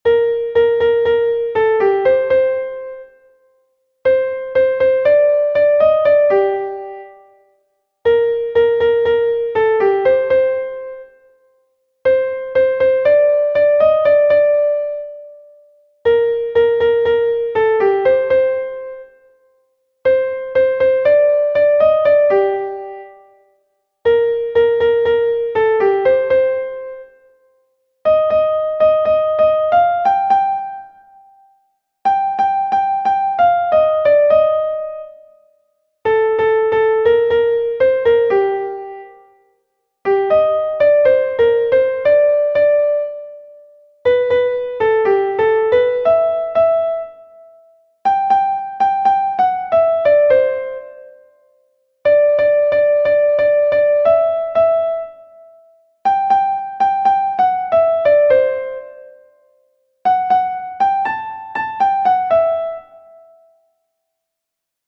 Kontakizunezkoa
AA1AA2BCD